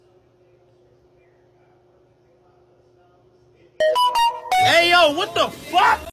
Why did they choose this as the new notifacation sound on android?